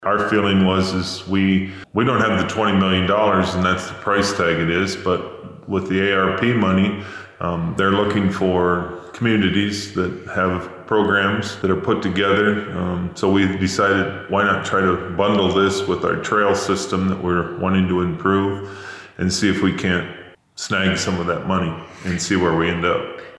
Here’s Humboldt County Board of Supervisors Chairman Bruce Reimers on the application for funding for the Reasoner Dam Project.